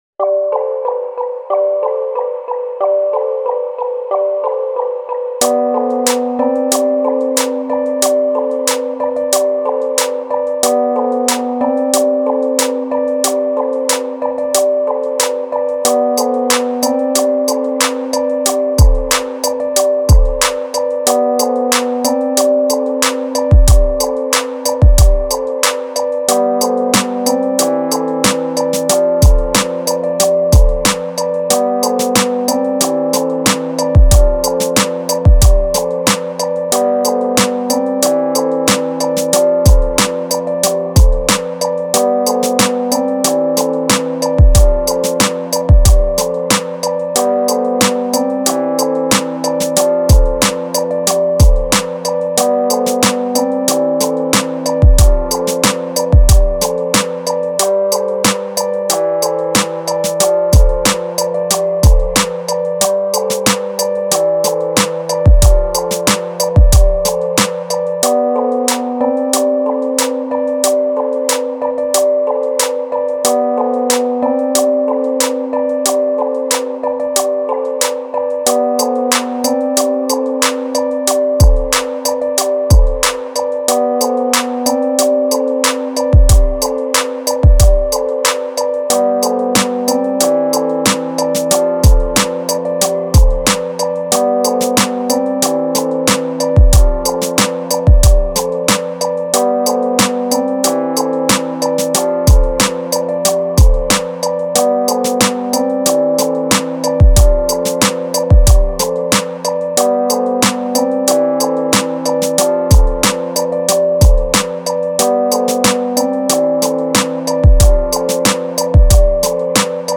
ORIGINAL INSTRUMENTALS